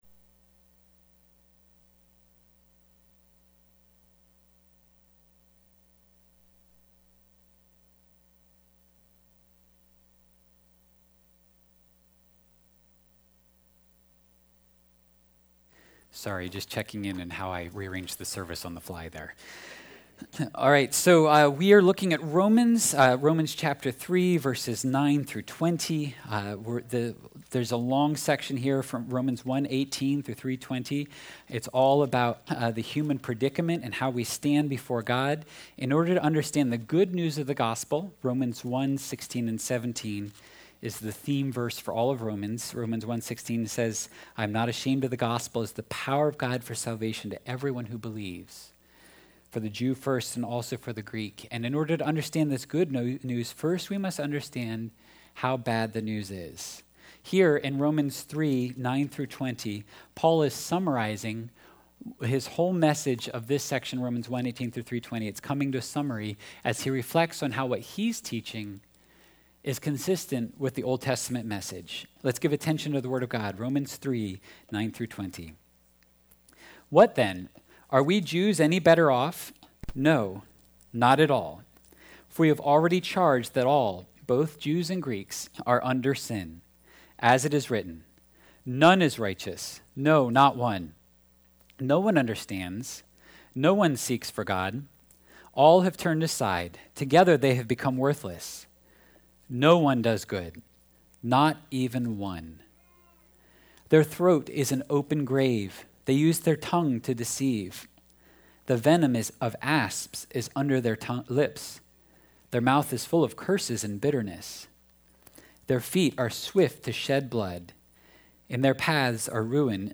6.11.23-sermon-audio.mp3